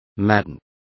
Complete with pronunciation of the translation of maddened.